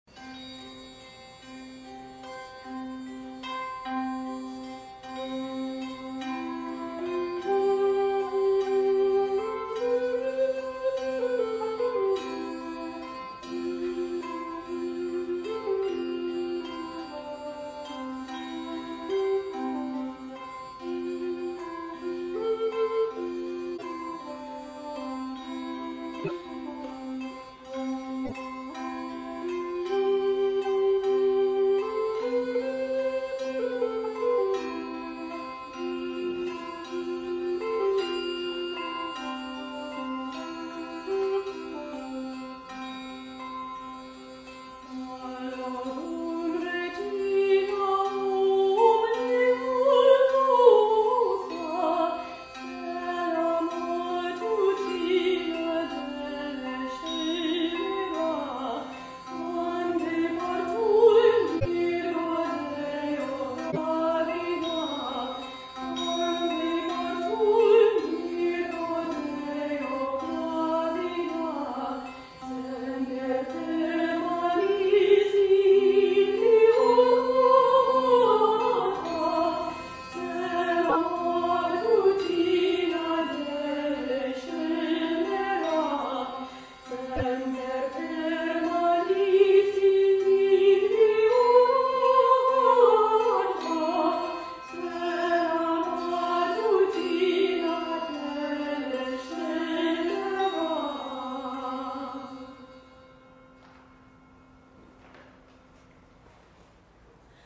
Click to download MP3 recordings of past concerts.